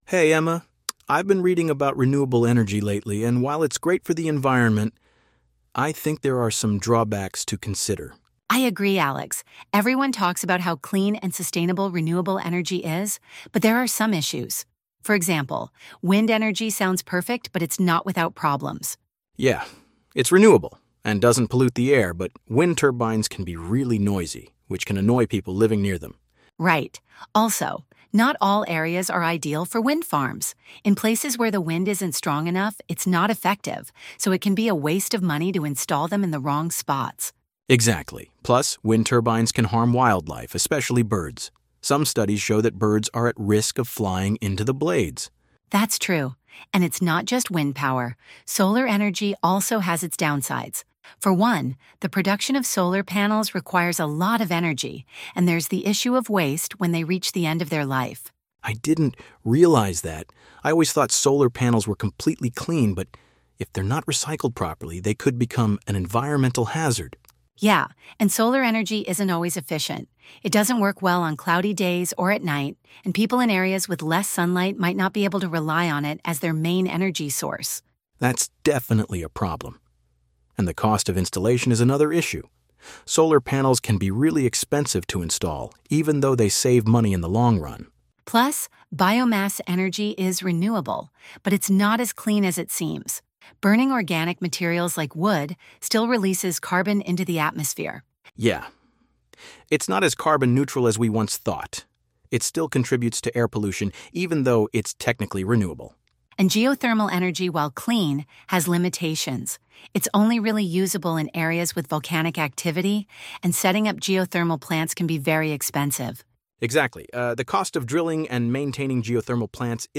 Unit-8-Conversation-and-questions.mp3